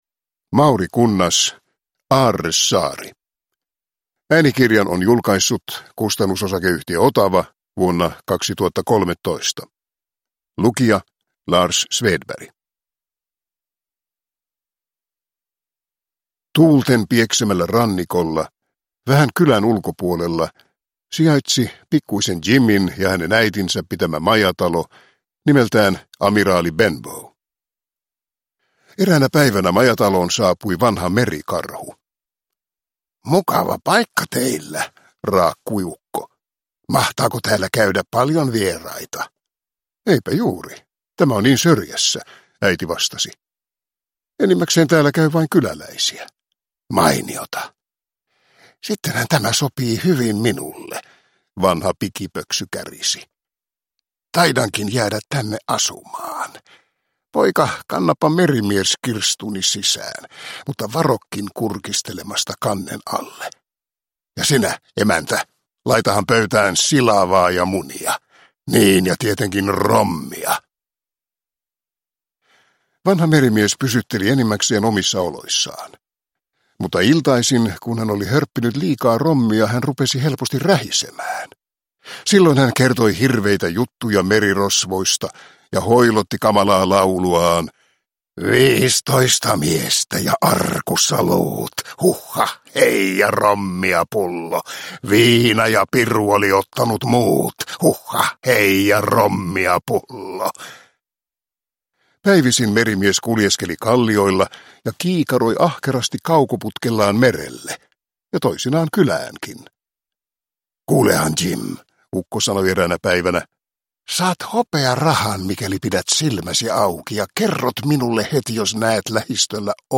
Aarresaari – Ljudbok – Laddas ner